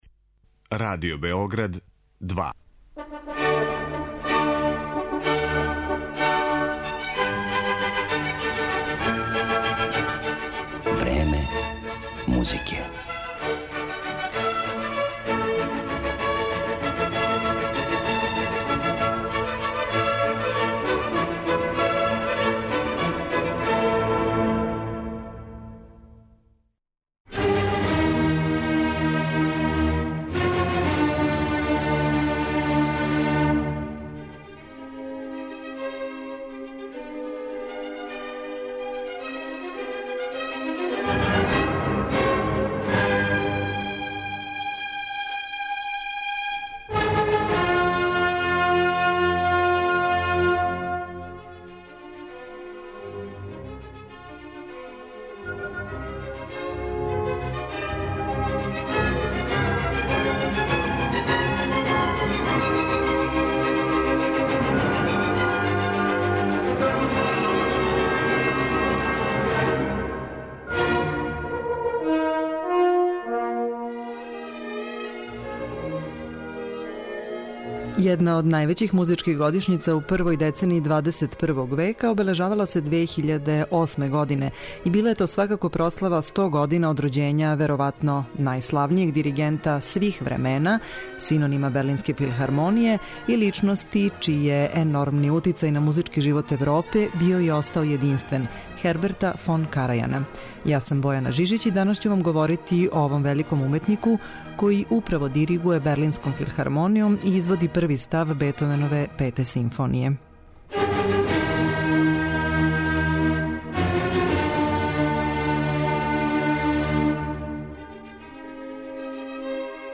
Поред изврсних уметникових тумачења пре свега немачке музике, моћи ћете да чујете и интервју са Карајаном објављен у британском музичком часопису "Грамофон" 2008. године, када је обележавано 100 година од рођења овог великог диригента.